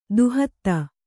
♪ duhatta